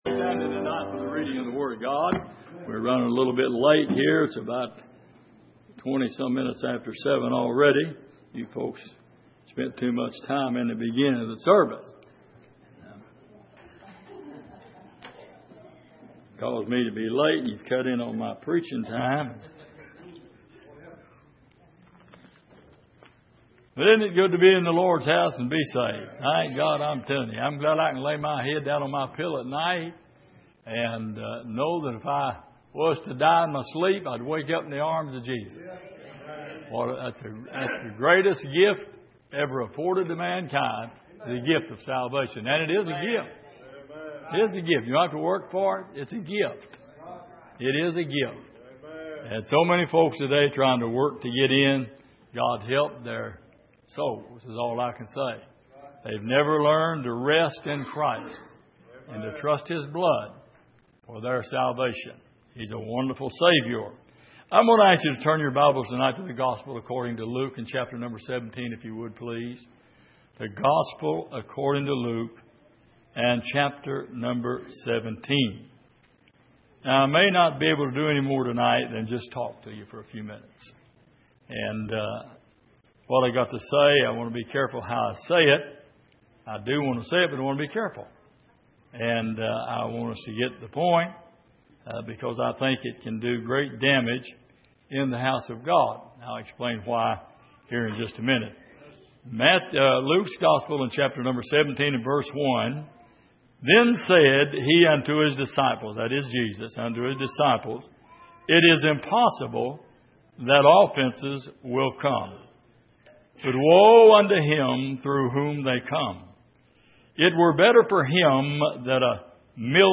Passage: Luke 17:1-6 Service: Sunday Evening